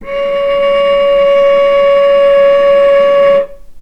healing-soundscapes/Sound Banks/HSS_OP_Pack/Strings/cello/ord/vc-C#5-mf.AIF at b3491bb4d8ce6d21e289ff40adc3c6f654cc89a0
vc-C#5-mf.AIF